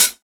• Studio Pedal Hi-Hat Sound B Key 79.wav
Royality free hat sound tuned to the B note. Loudest frequency: 7645Hz
studio-pedal-hi-hat-sound-b-key-79-kYr.wav